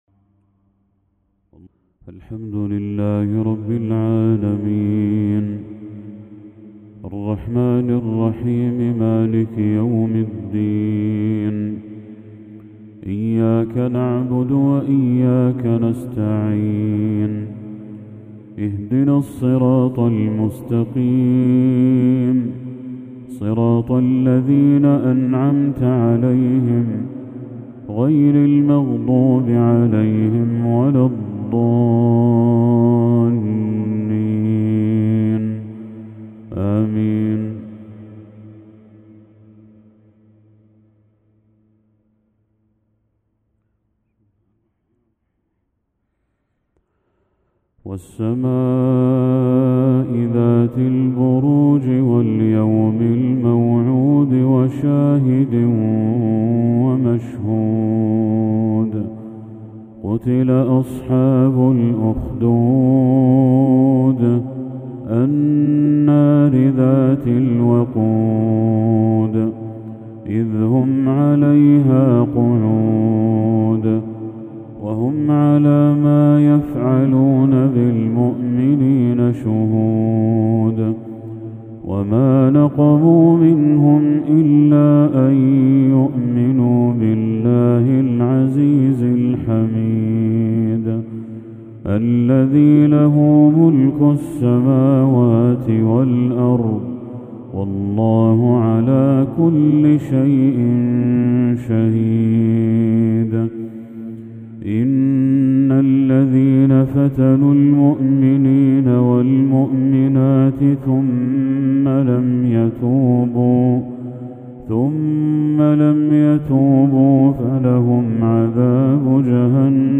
تلاوة عذبة للشيخ بدر التركي سورة البروج كاملة | مغرب 4 محرم 1446هـ > 1446هـ > تلاوات الشيخ بدر التركي > المزيد - تلاوات الحرمين